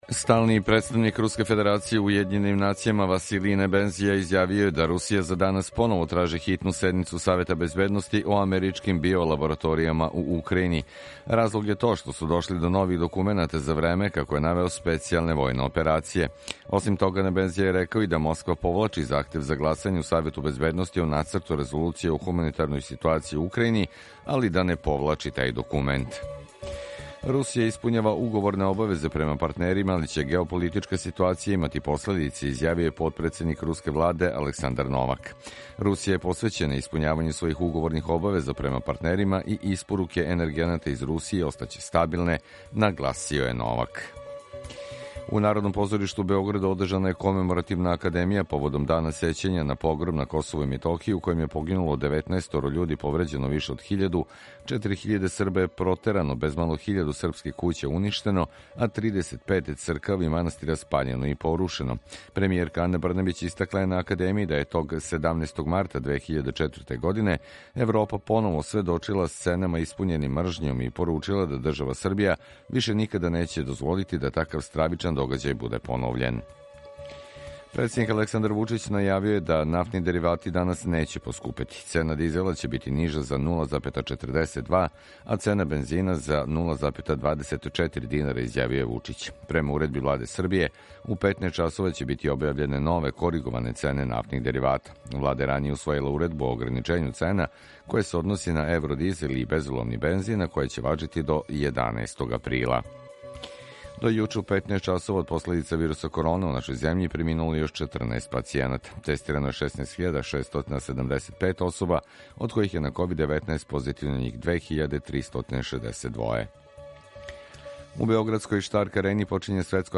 Емисију реализујемо са Радијом Републике Српске из Бањалуке и Радијом Нови Сад
Јутарњи програм из три студија
У два сата, ту је и добра музика, другачија у односу на остале радио-станице.